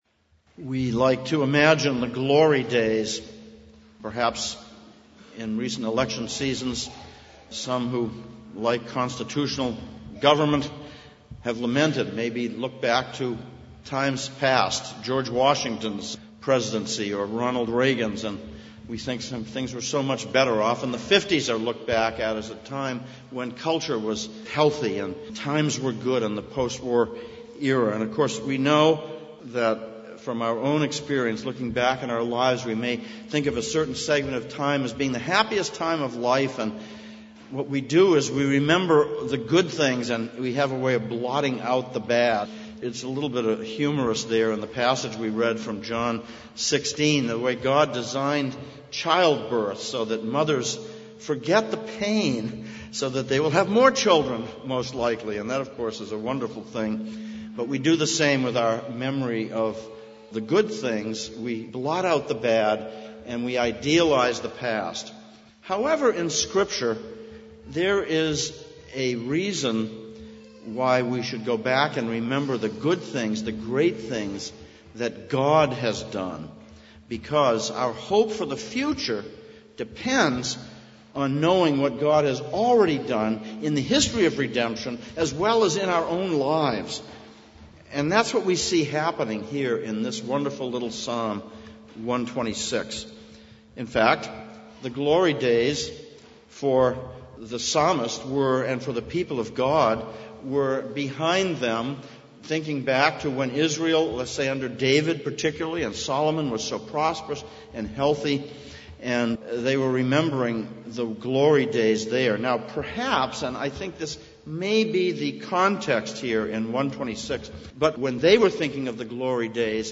Psalms of Ascents Passage: Psalm 126:1-6, John 16:16-24 Service Type: Sunday Morning « 5.